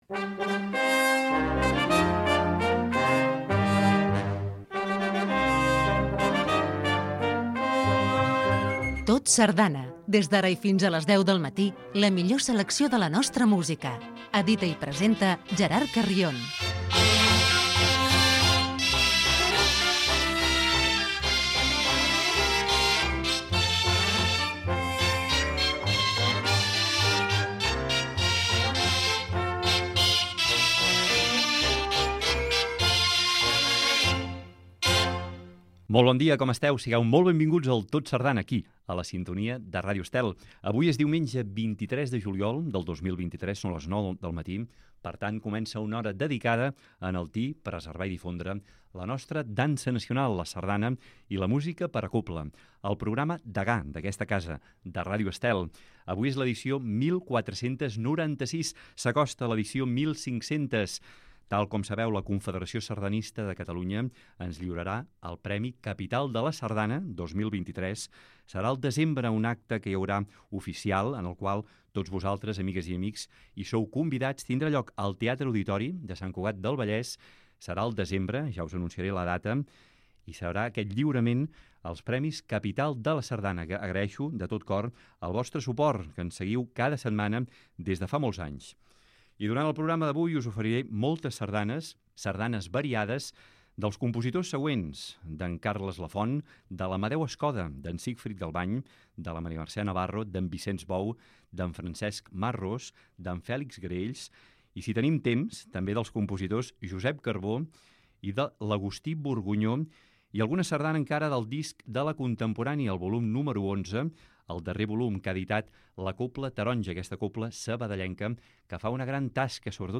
Espai per difondre la sardana.